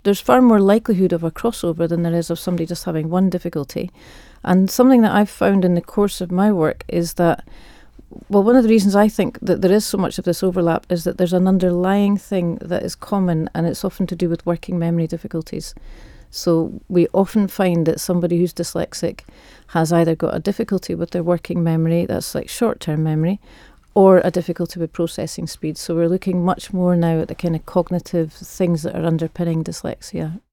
Edinburgh-based specialist in the condition